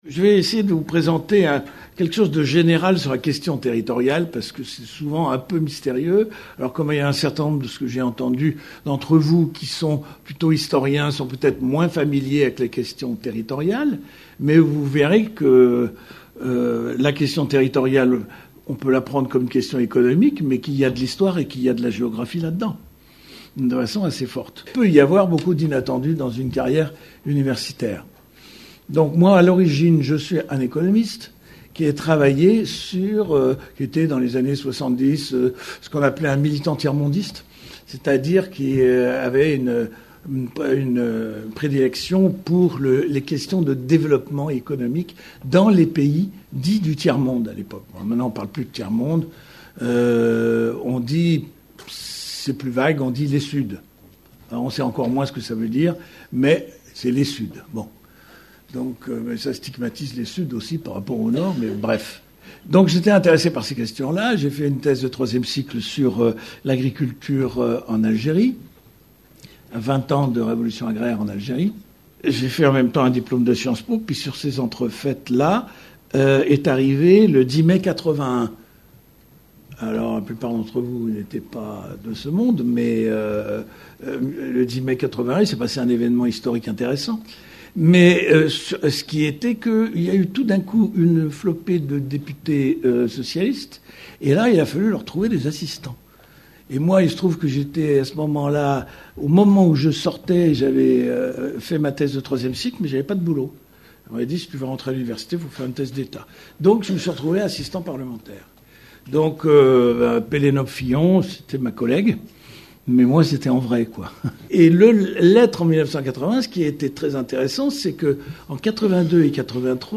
Cours/Séminaire
Lieu de réalisation MRSH Caen